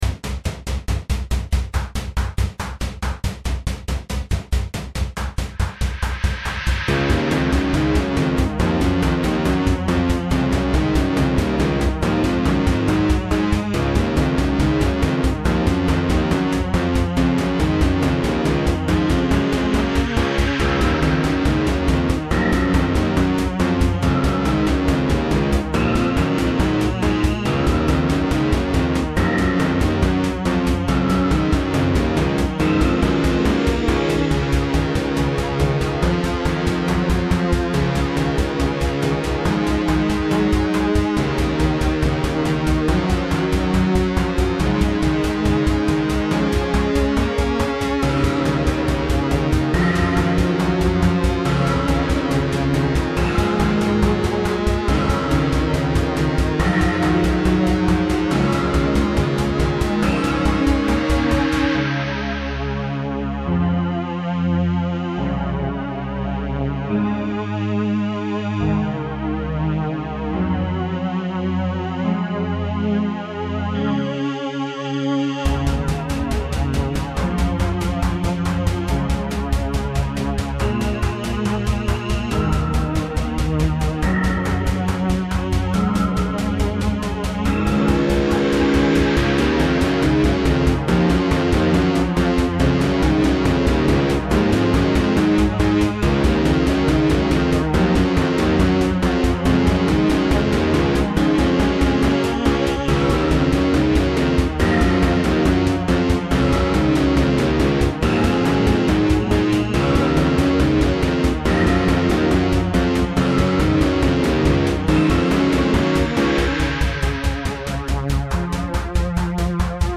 A standard battle song.